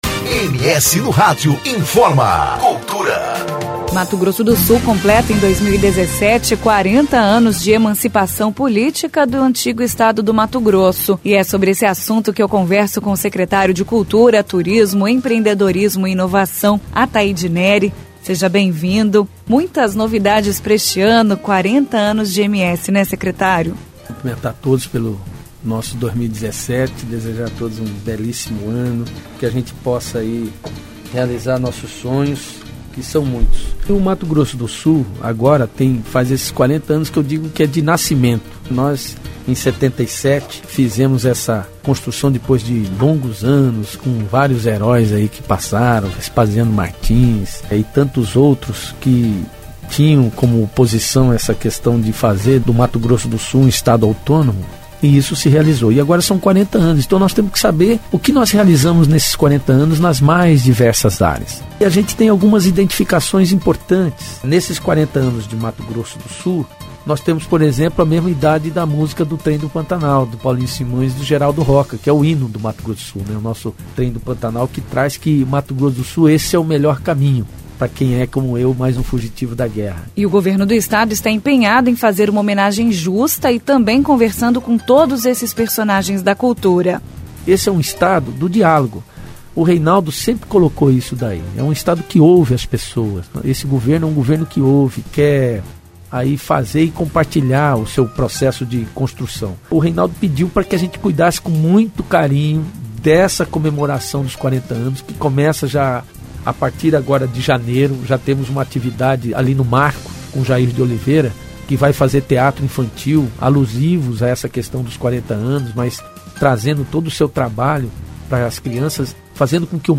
Entrevista com o secretário de Estado de Cultura, Turismo, Empreendedorismo e Inovação, Athayde Nery.